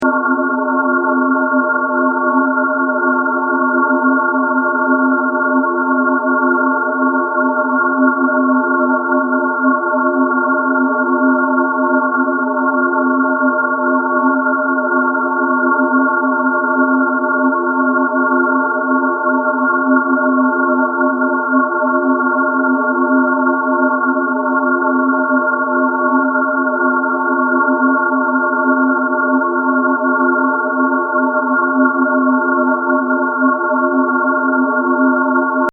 listen to a quasar